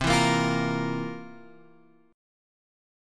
C11 chord {8 7 8 x 6 6} chord